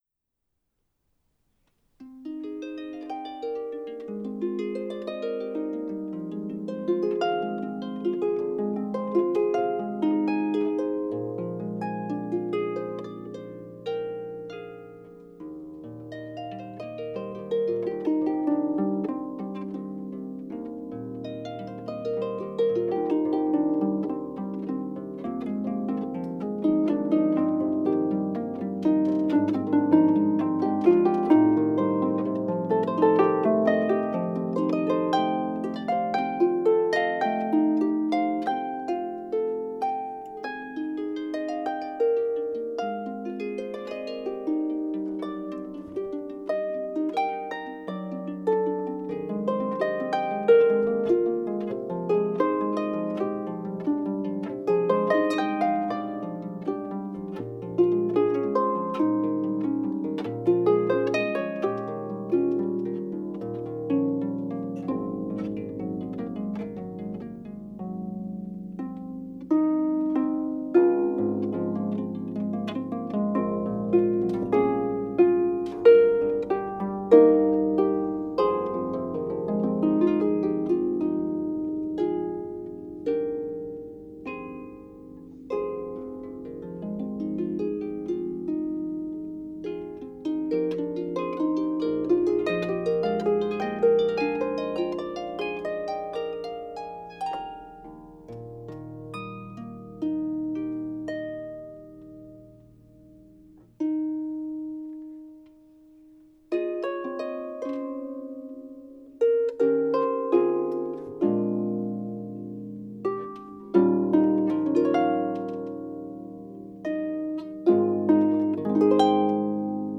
Pedal Harp